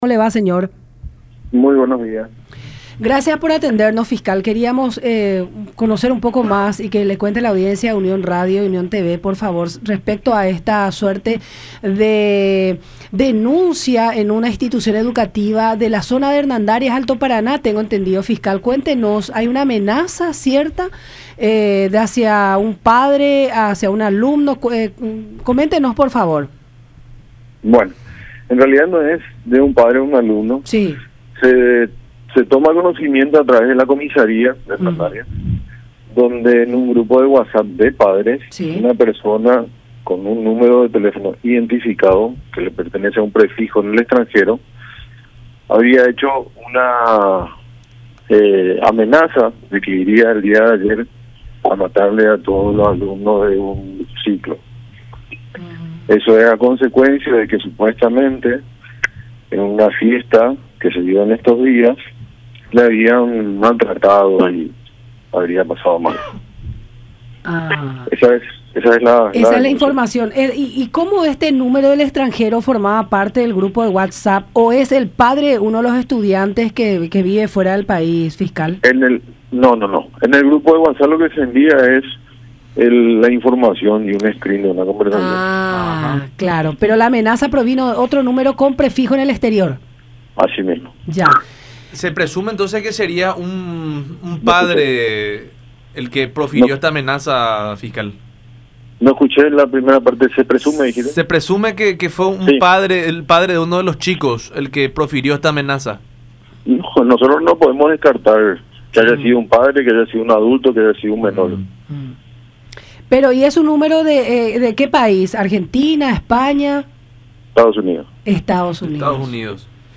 “Desde un número de Estados Unidos, hicieron una amenaza. Irían a matarles a todos los alumnos del tercer ciclo. Era a consecuencia porque en una fiesta celebrada en días recientes le habían maltratado y la había pasado mal”, dijo el fiscal del caso, Adolfo Santander, en diálogo con La Mañana De Unión a través de Unión TV y radio La Unión, indicando que el caso se tomó conocimiento a través de una denuncia presentada en la comisaría de Hernandarias.